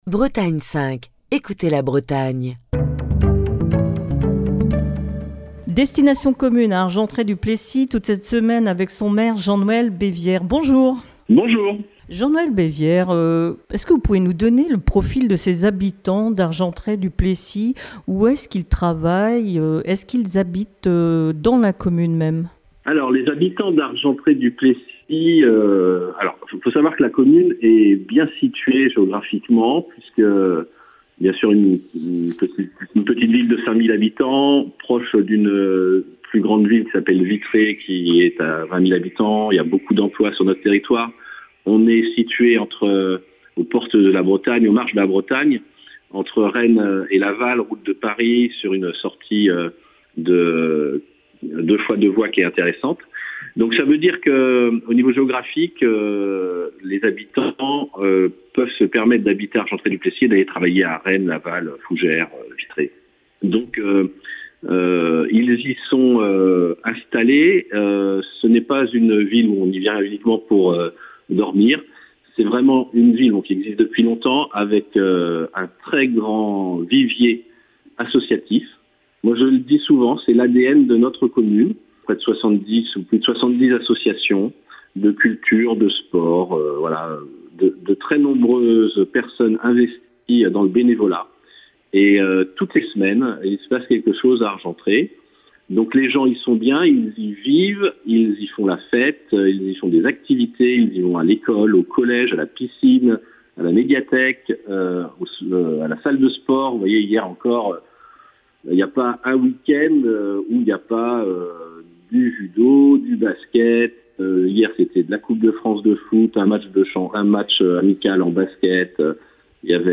Destination commune passe cette semaine à Argentré-du-Plessis en Ille-et-Vilaine. Jean-Noël Bévière, le maire d'Argentré-du-Plessis, vous invite à découvrir sa commune